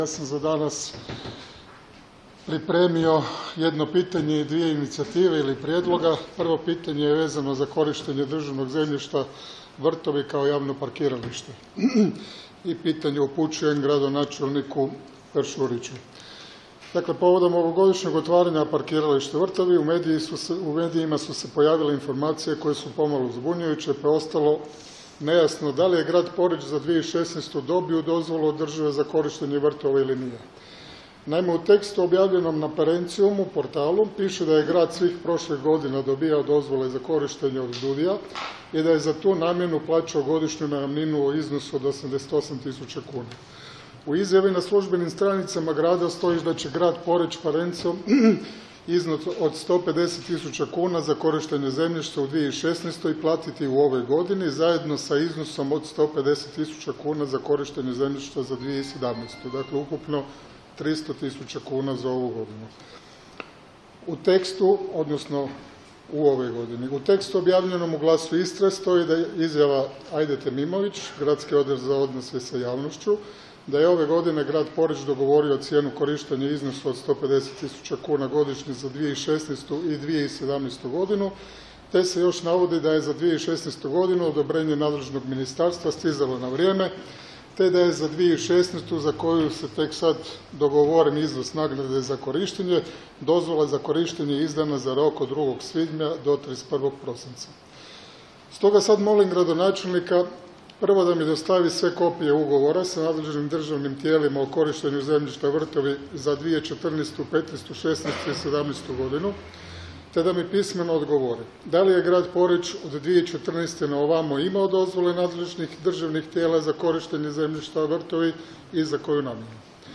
No, Gašparac je nastavio svoje izlaganje, nadvikujući se sa Pauletićem.
Cijelu diskusiju sa odgovorom gradonačelnika Lorisa Peršurića možete poslušati ovdje: [dok1] Gradonačelnik je vijećniku odgovorio isto što i nama prije mjesec dana: pojasnio je kako je za 2016. i 2017. godinu sa Ministarstvom imovine sklopljen ugovor o korištenju Vrtova.